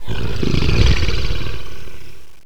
groan1.mp3